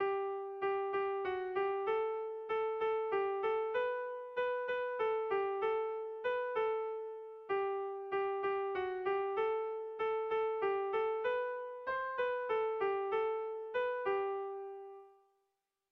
Kontakizunezkoa
Estructura musical
ABAB